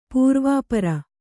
♪ pūrvāpara